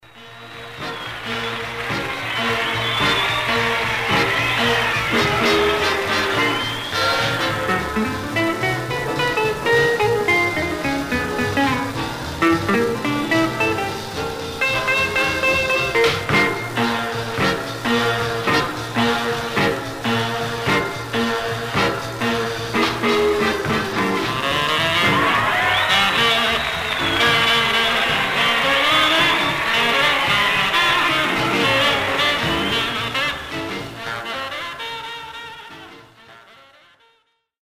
Surface noise/wear Stereo/mono Mono
R&B Instrumental